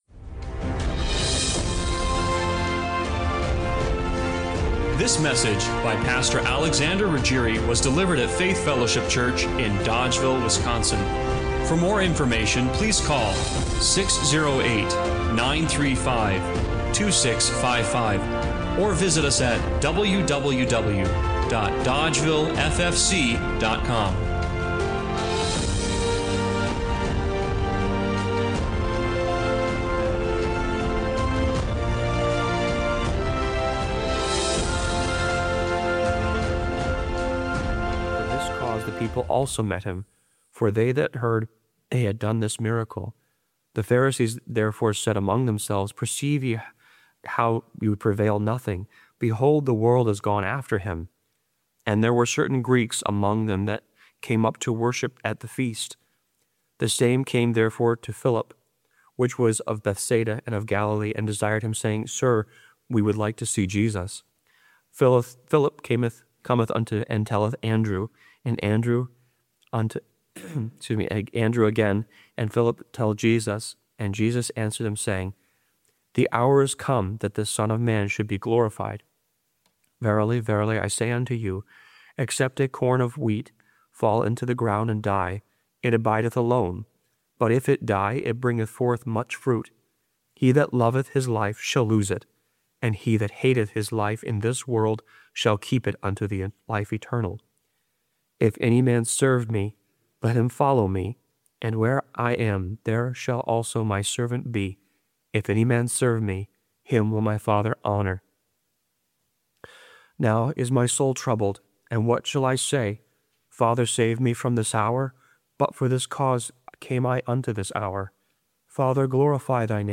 John 12:12-32 Service Type: Sunday Morning Worship What if you could pull a number and finally know when your moment would come?